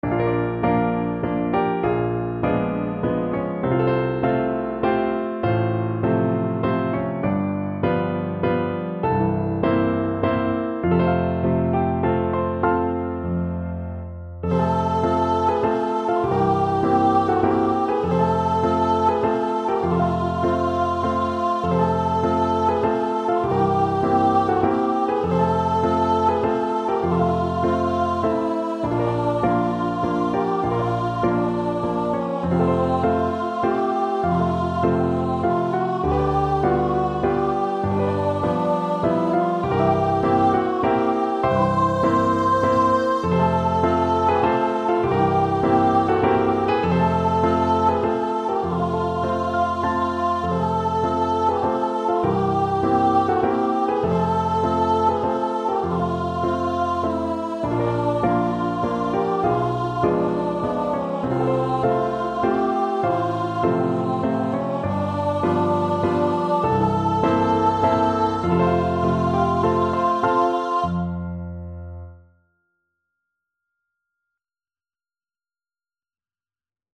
~ = 100 Slowly and dreamily
3/4 (View more 3/4 Music)
Classical (View more Classical Voice Music)